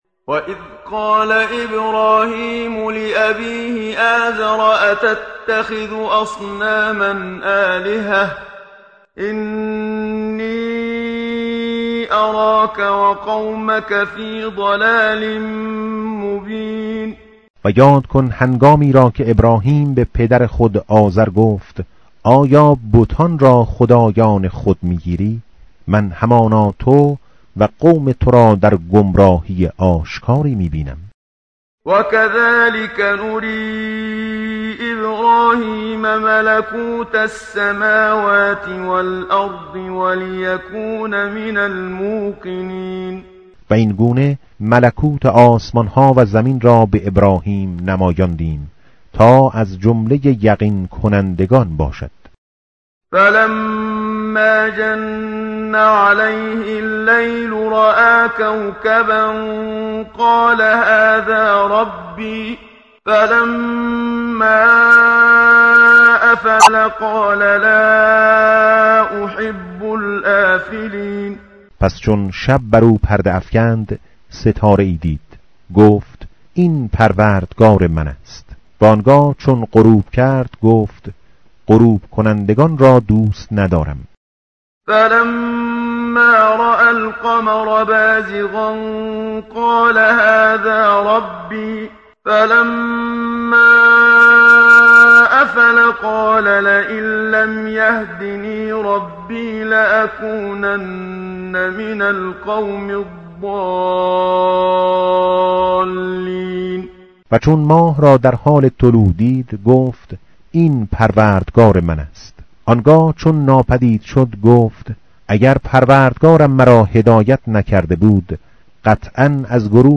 متن قرآن همراه باتلاوت قرآن و ترجمه
tartil_menshavi va tarjome_Page_137.mp3